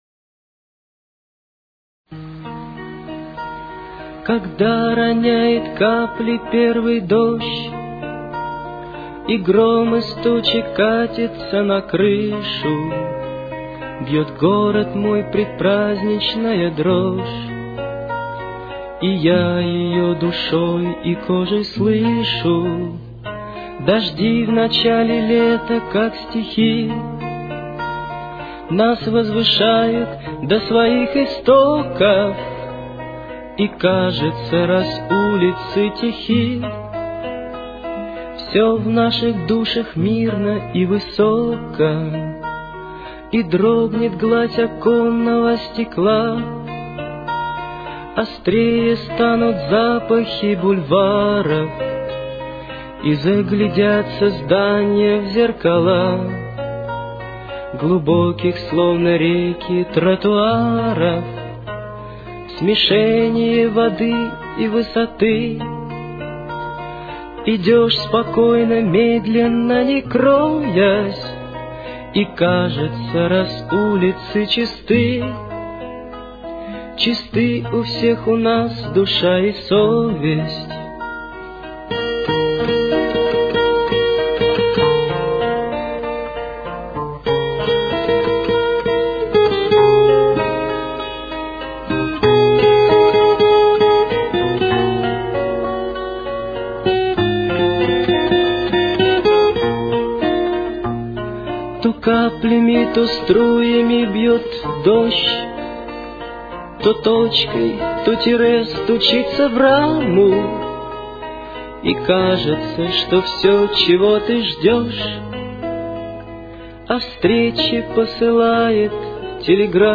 Тональность: Ми-бемоль минор. Темп: 101.